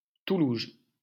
来自 Lingua Libre 项目的发音音频文件。 语言 InfoField 法语 拼写 InfoField Toulouges 日期 2018年8月9日 来源 自己的作品